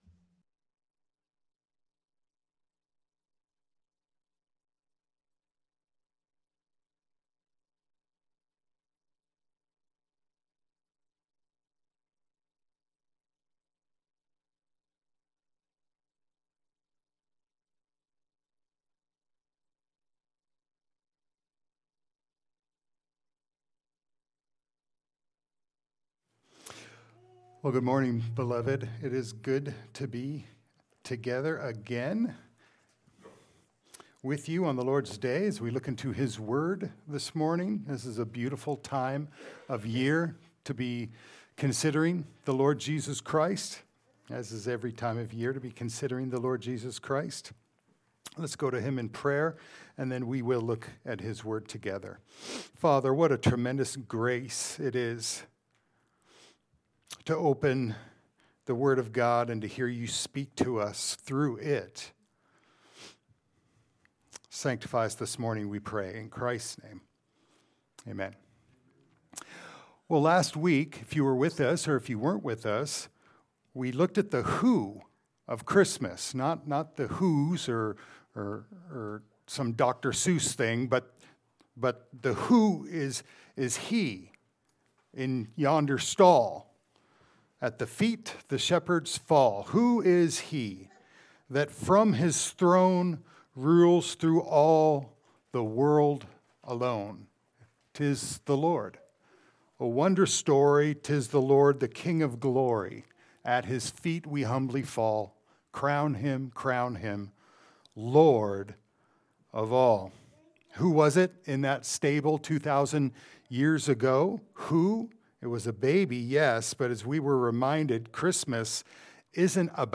Passage: Matthew 1:18-25 Service Type: Sunday Service